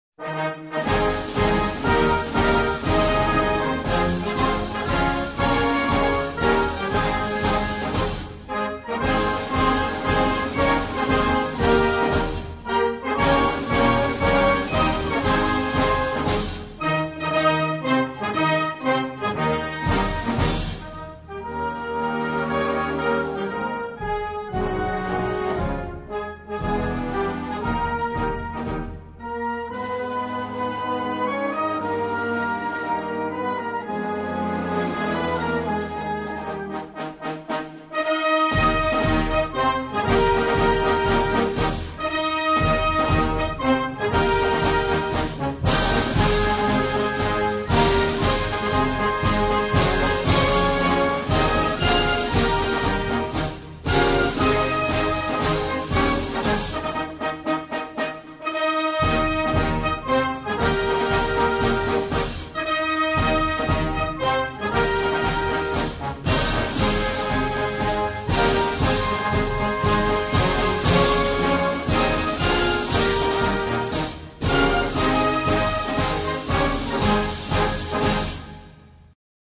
Hymne Français
Hymne-National-France.mp3